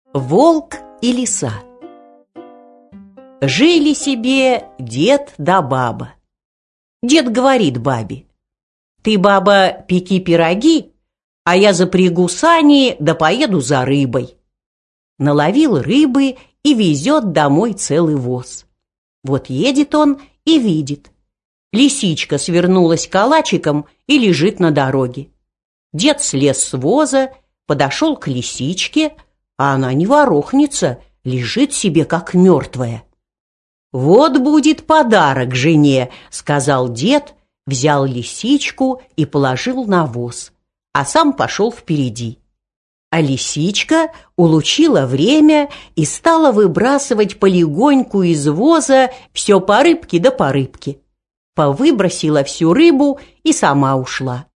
Аудиокнига Волк и Лиса | Библиотека аудиокниг